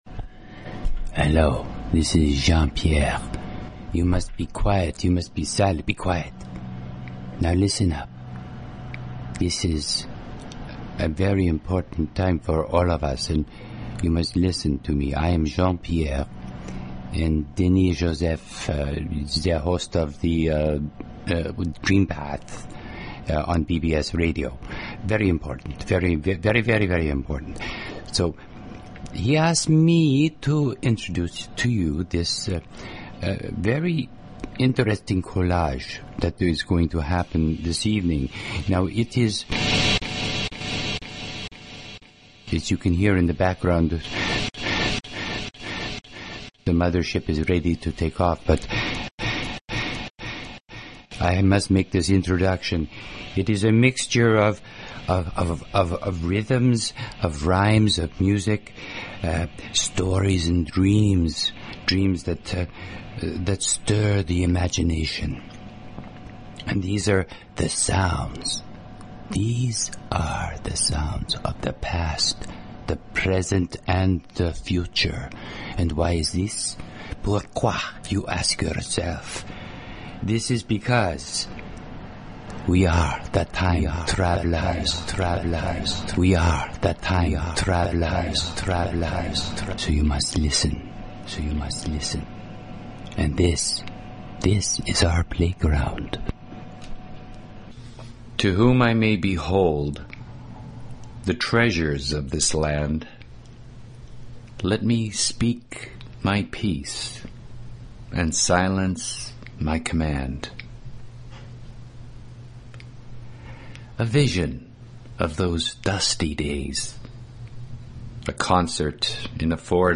Talk Show Episode, Audio Podcast, DreamPath and Courtesy of BBS Radio on , show guests , about , categorized as
Comedy, music, and a continuous weaving of interviews and story telling (DreamPath style) covering numerous alternative thought topics.